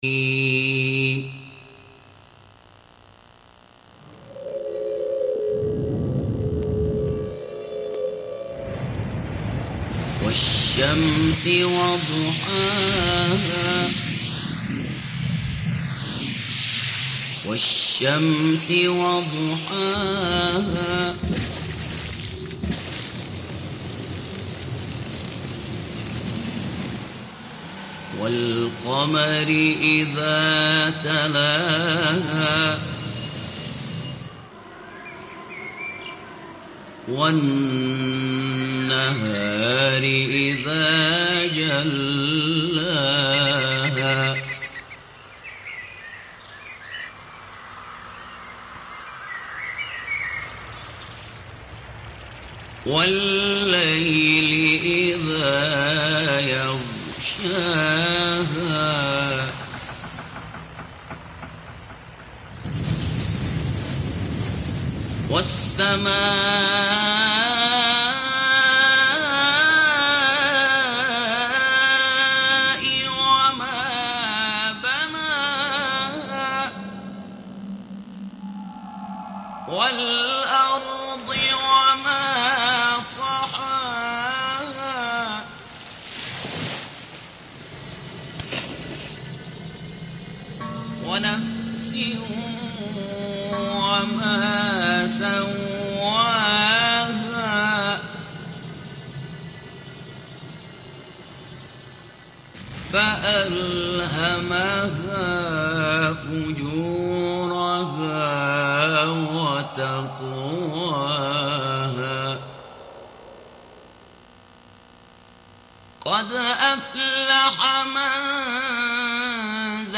گزارش نشست
نشستی با جمعی از اعضای هیأت علمی پژوهشگاه علوم انسانی و مطالعات فرهنگی و اندیشمندان و پژوهشگران فلسفه و ادبیات در سالن حکمت پژوهشگاه علوم انسانی و مطالعات فرهنگی برگزار شد.
پس از آن سخنرانان به ترتیب مطالب مختلفی در حوزه مسائل مربوط به فلسفه و ارتباط آن با ادبیات ارائه کردند.